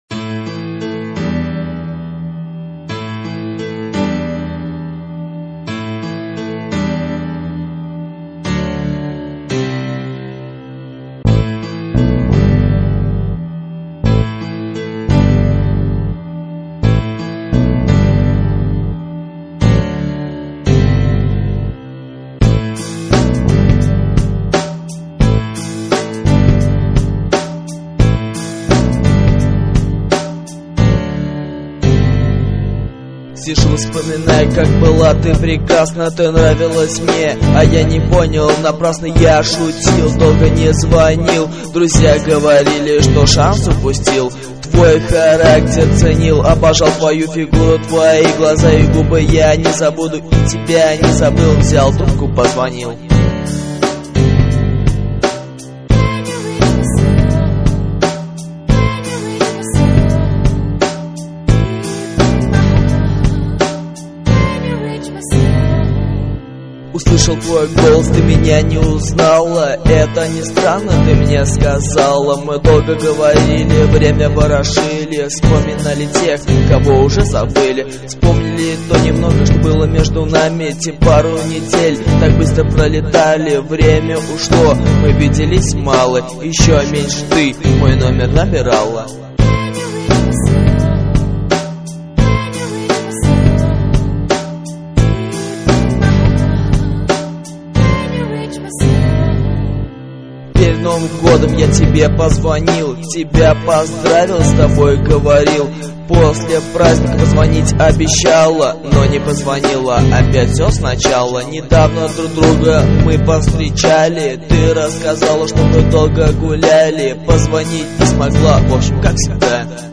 Рэп (46715)